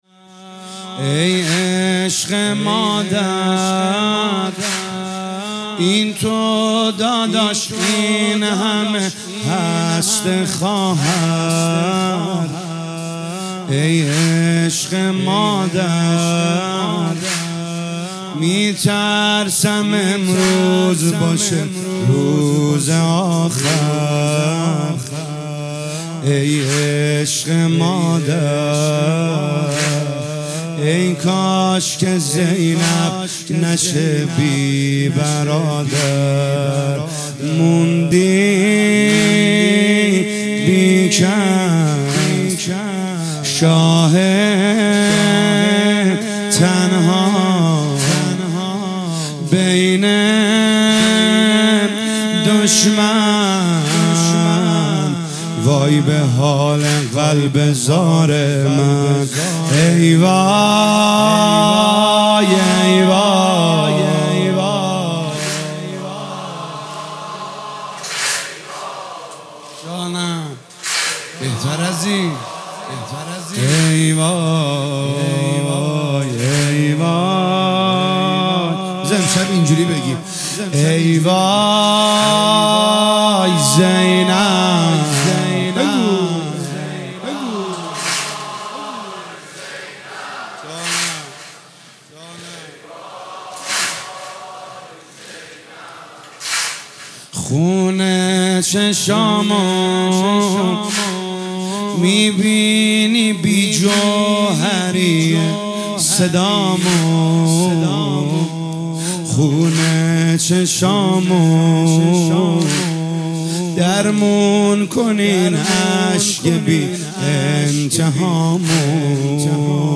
مداحی واحد شب چهارم محرم 1445
هیئت خادم الرضا قم